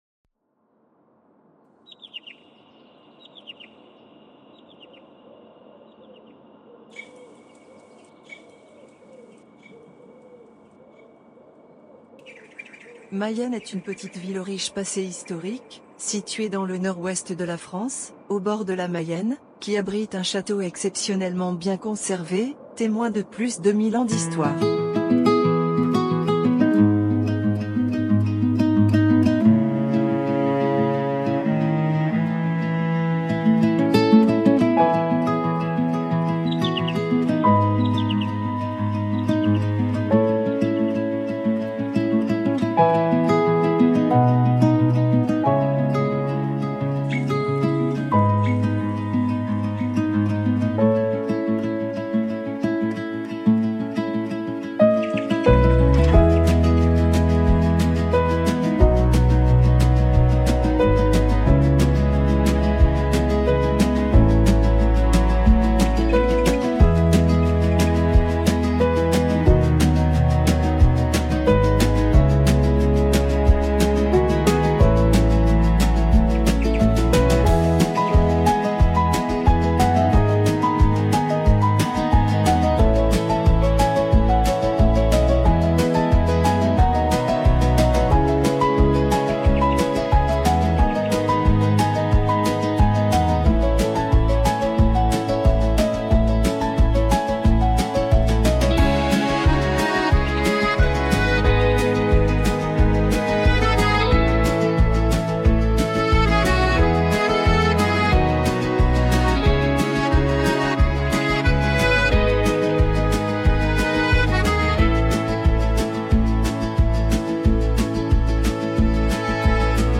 soundscape